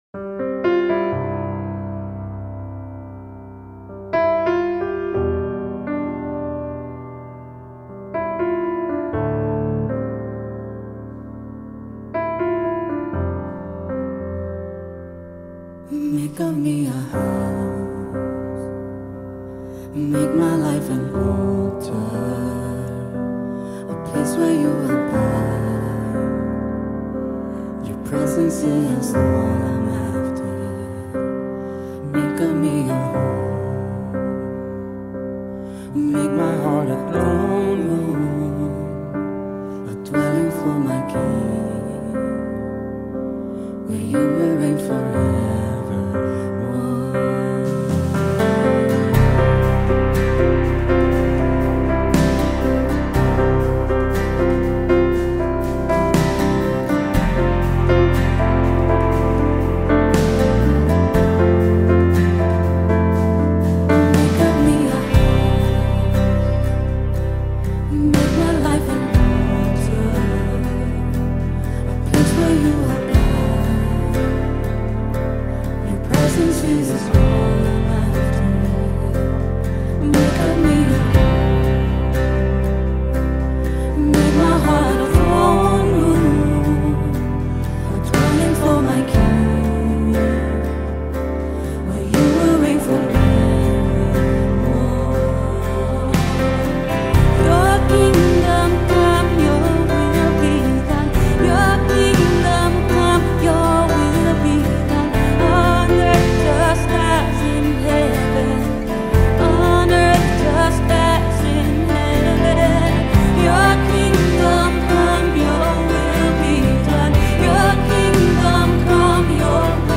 490 просмотров 226 прослушиваний 19 скачиваний BPM: 120